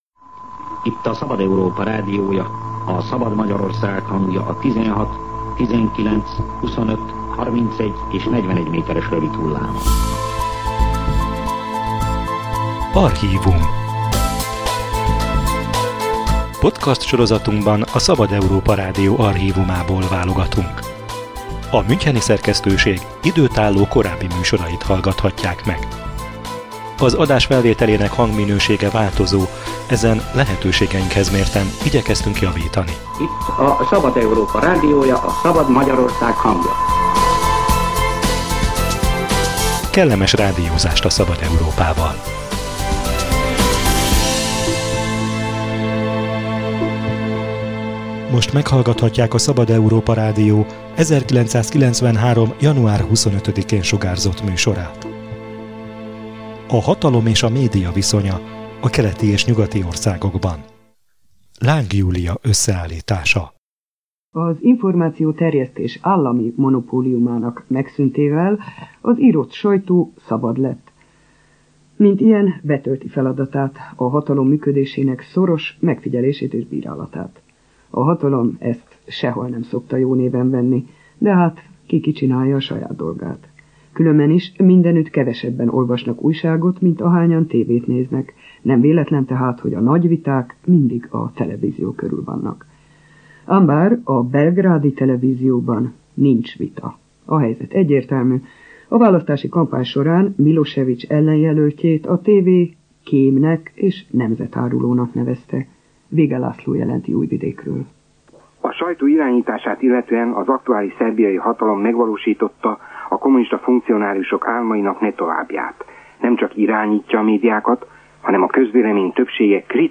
Stratégiai ágazatnak nevezte a médiát Orbán Viktor kormányfő politikai igazgatója egy szerdai konferencián. A Szabad Európa Rádió archívumából egy olyan műsort idézünk vissza, amely a hatalom és média harminc évvel ezelőtti viszonyáról szól.